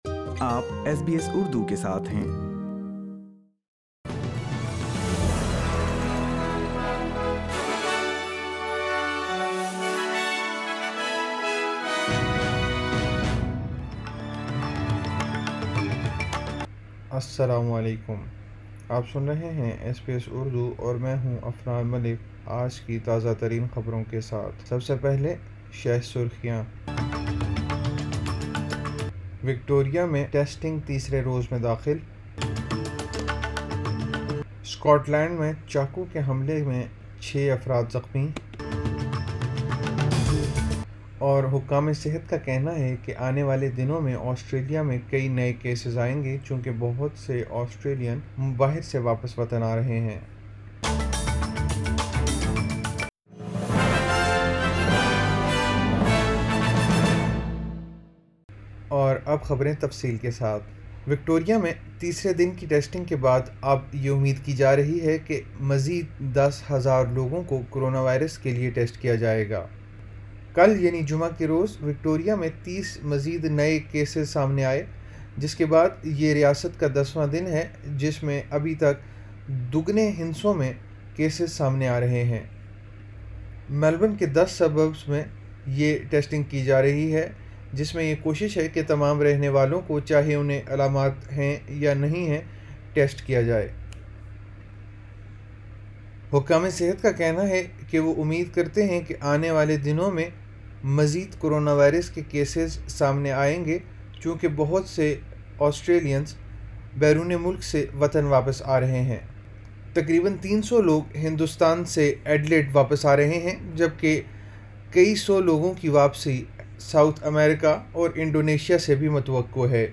SBS Urdu News 27 June 2020